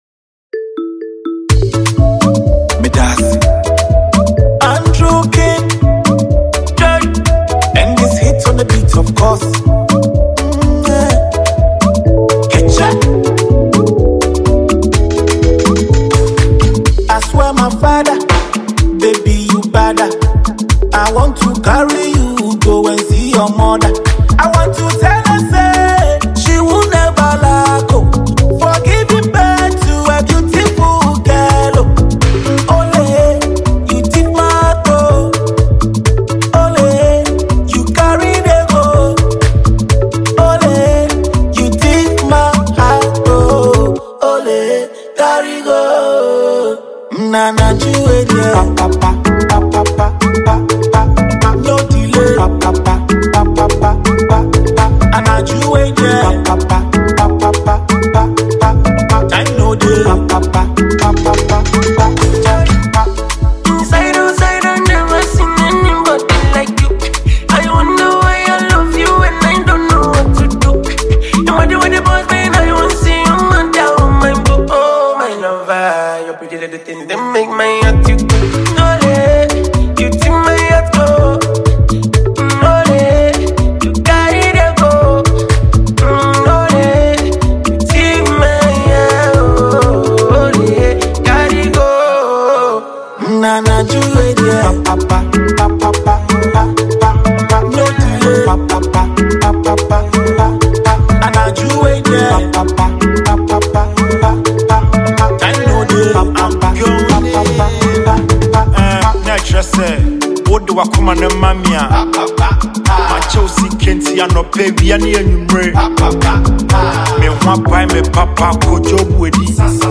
Ghanaian music duo
captivating afrobeat new song
another Ghanaian afrobeat highlife singer.